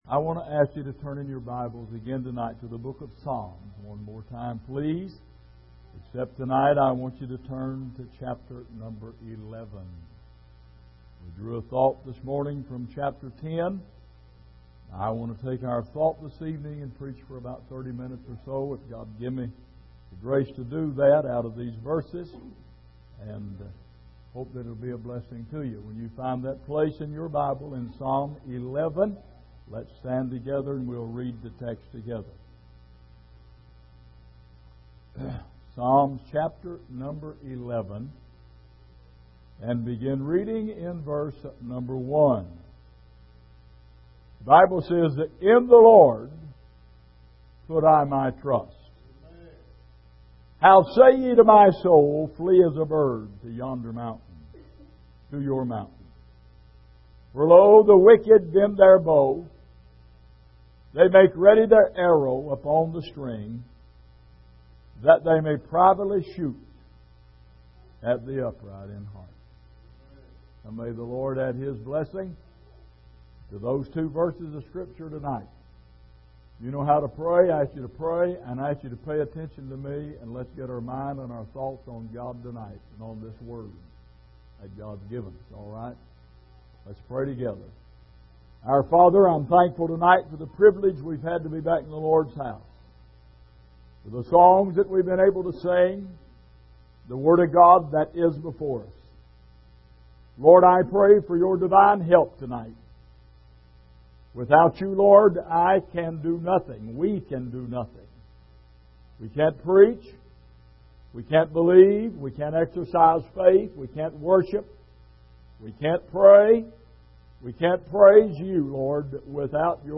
Exposition of the Psalms Passage: Psalm 11:1-2 Service: Sunday Evening Are You Flying In The Wrong Direction?